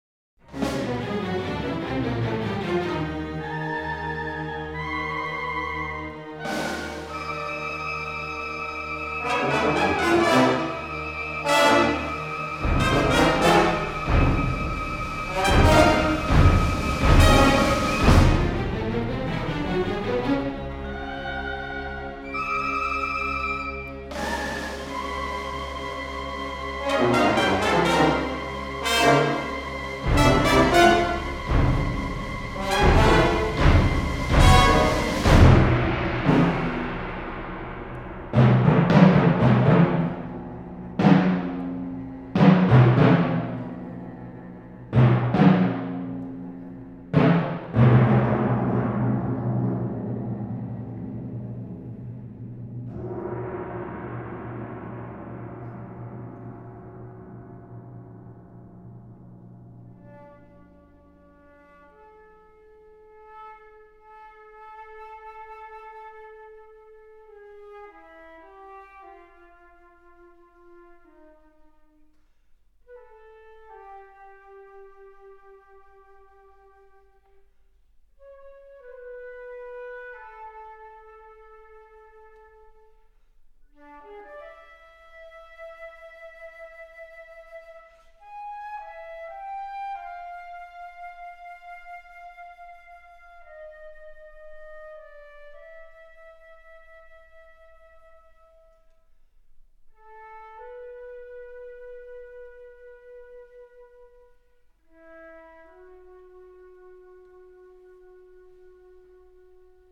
诡诵多变、气势惊人
刚柔并济的乐风，展现兵法中水攻的柔、火攻的猛；灵活的旋律激发您的思考，带您学会掌握时机、一路过关斩将。